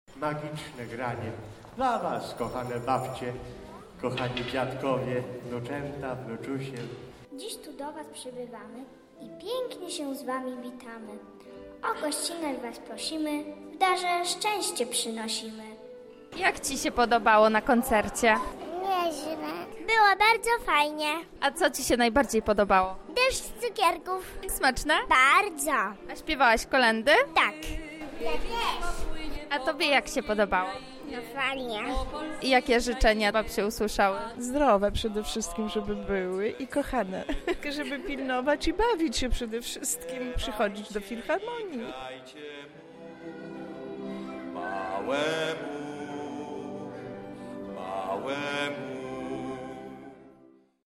Podczas wczorajszego wydarzenia „kolęda z babcią i dziadkiem” wystąpił zespół harfowy Wiktorska Harp Open.
Widowisko muzyczne zostało wystawione w Filharmonii Lubelskiej.